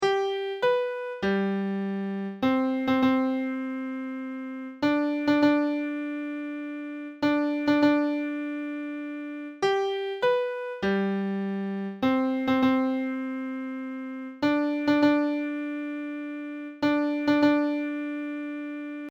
Zulu chant - 4 Parts
The chant begins with part 2 singing alone, very softly.